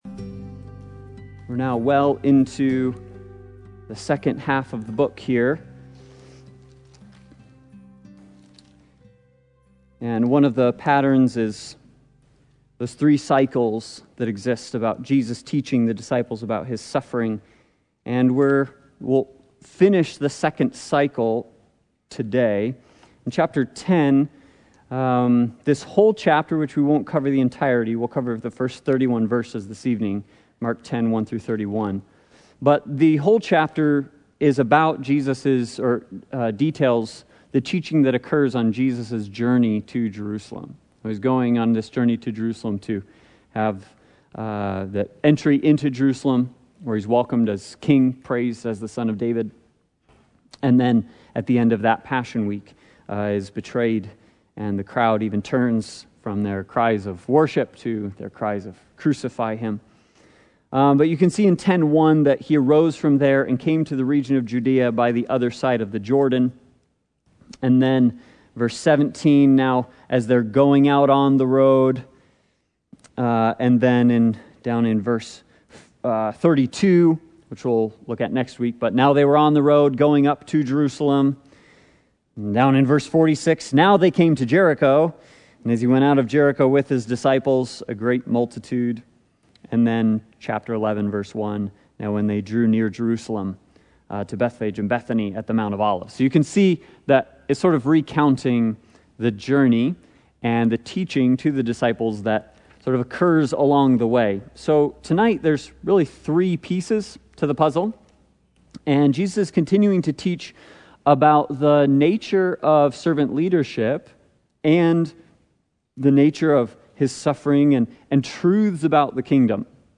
Passage: Mark 10:1-31 Service Type: Sunday Bible Study